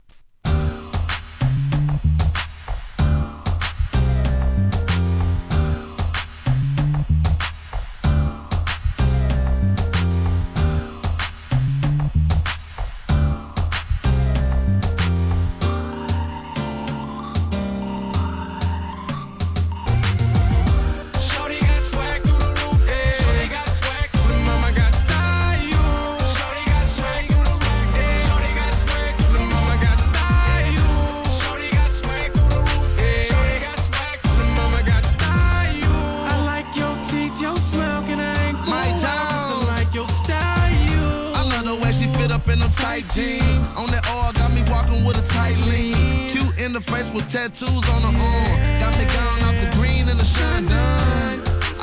1. HIP HOP/R&B
DJ USEに RE-EDITしている人気シリーズ!!!